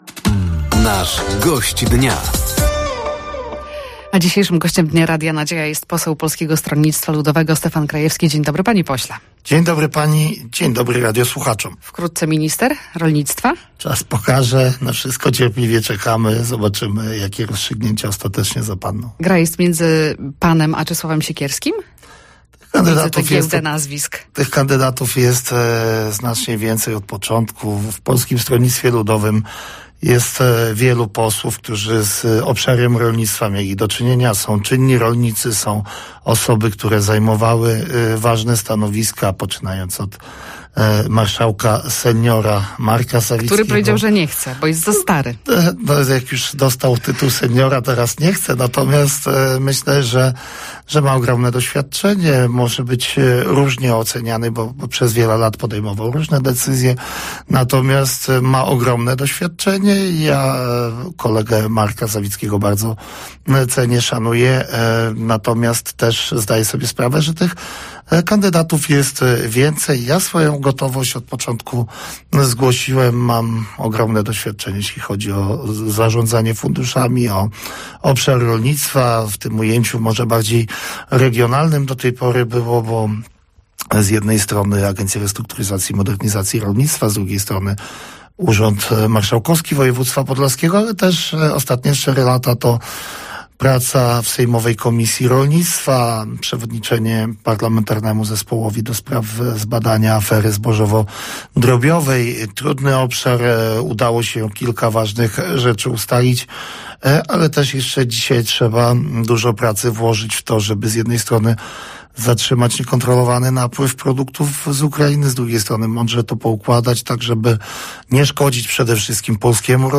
Gościem Dnia Radia Nadzieja był Stefan Krajewski, poseł Polskiego Stronnictwa Ludowego. Tematem rozmowy było między innymi wojsko w województwie podlaskim, Centralny Port Komunikacyjny i to, czy poseł będzie ministrem rolnictwa.